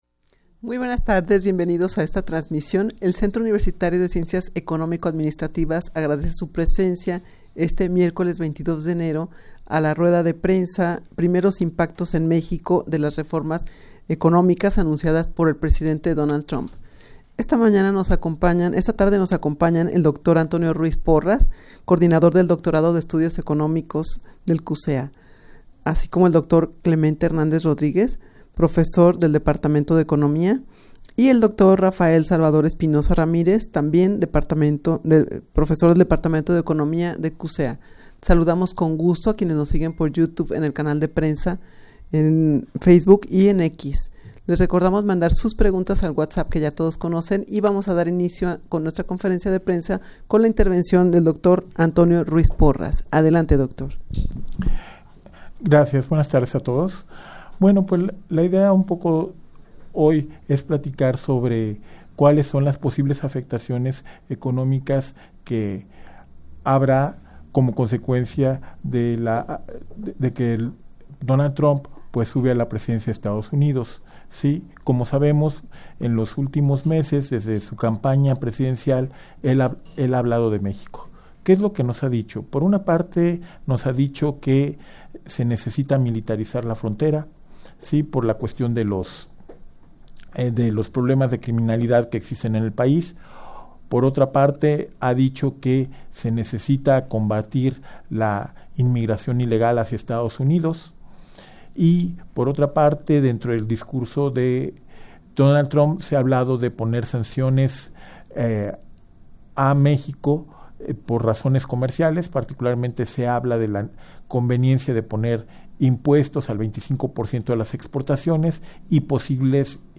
Audio de la Rueda de Prensa
rueda-de-prensa-primeros-impactos-en-mexico-de-las-reformas-economicas-anunciadas-por-el-presidente-trump.mp3